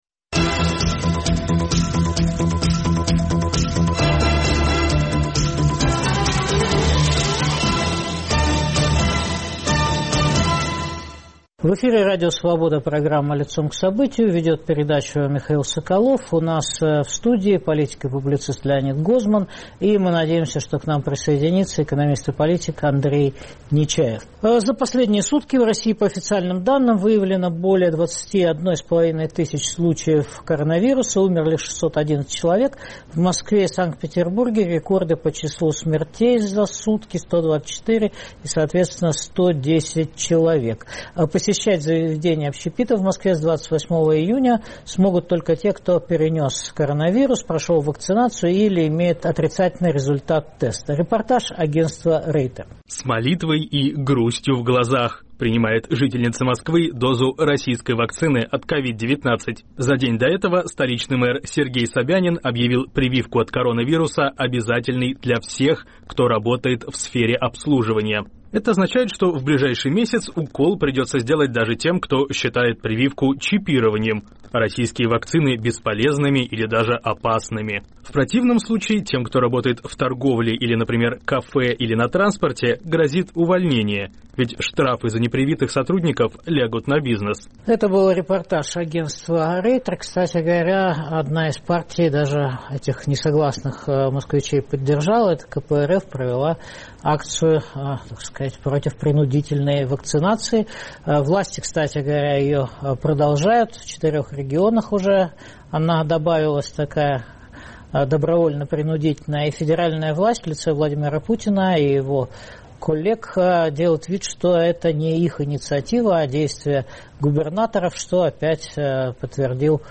Политический курс режима Путина обсуждаем с политиком Леонидом Гозманом и экономистом Андреем Нечаевым.